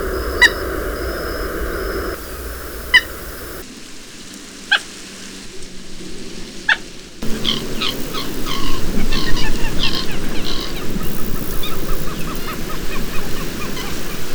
Common Moorhen
Gallinula chloropus
VOZ: Un variado cloqueo y también tartamudea un trompeteo.